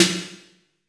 AMBIENT S8-R 4.wav